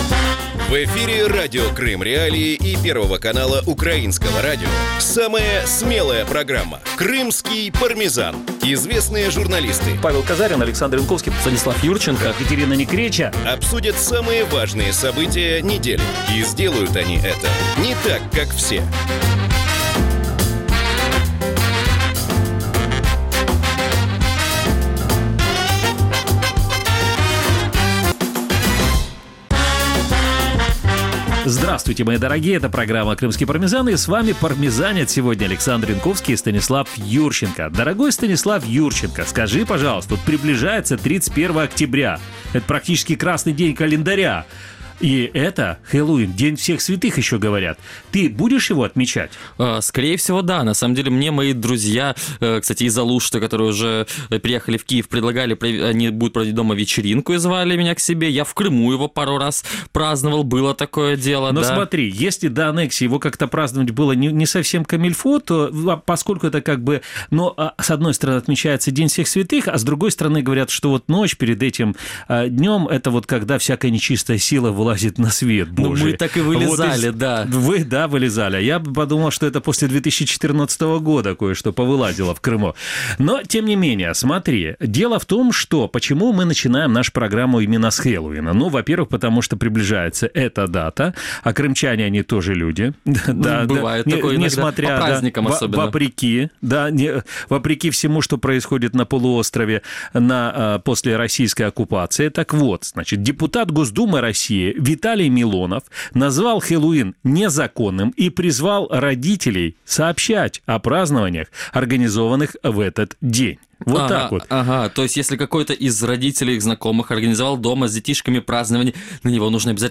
Программа звучит каждую субботу в 17:30 в эфире Радио Крым.Реалии на 105.9 ФМ, а также в эфире первого канала Украинского радио на 549 АМ.